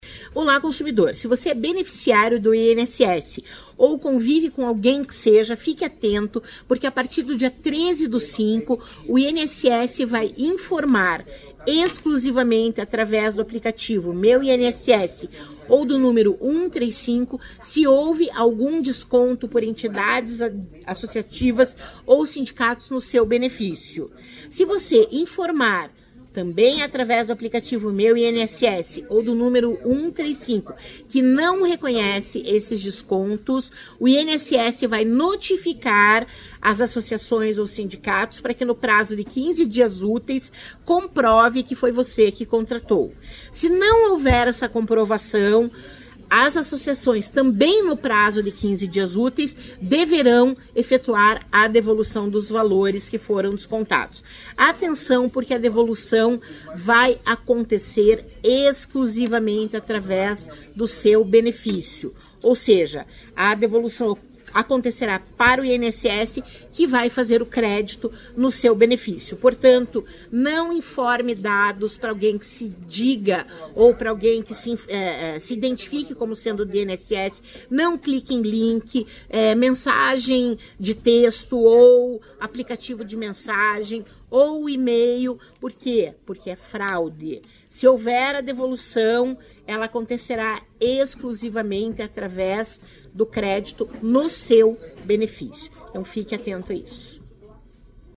Sonora da coordenadora do Procon, Cláudia Silvano, sobre as orientações para beneficiários do INSS para restituição de valores em relação a fraude dos descontos por entidades associativas